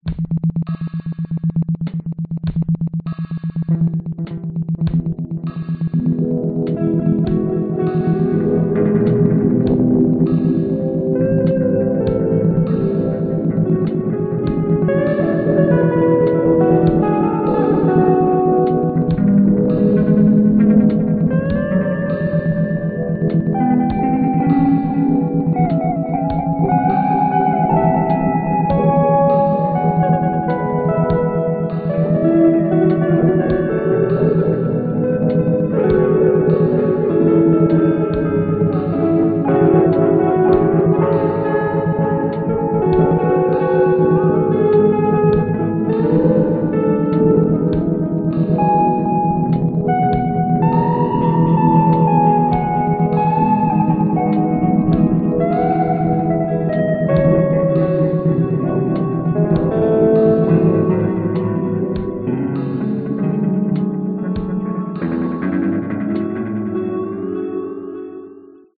唱诗班会议黑暗小巷
描述：方舟合唱团
Tag: 90 bpm Rap Loops Choir Loops 1.80 MB wav Key : G